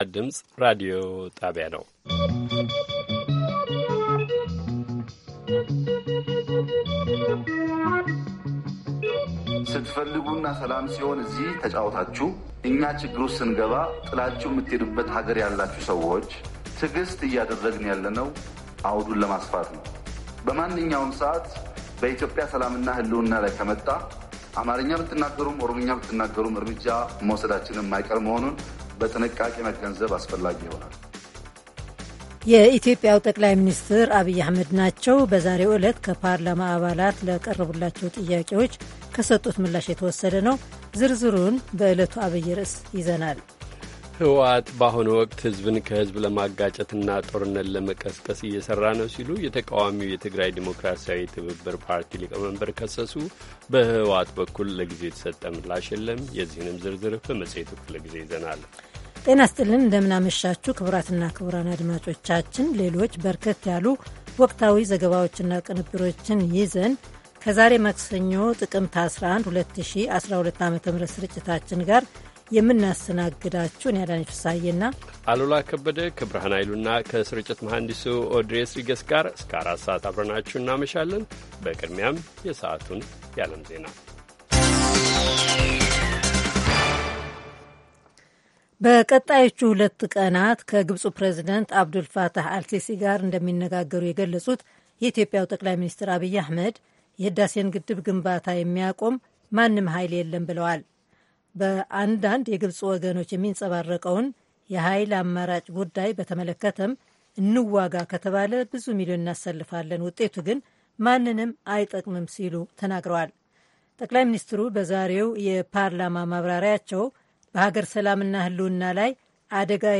ማክሰኞ፡- ከምሽቱ ሦስት ሰዓት የአማርኛ ዜና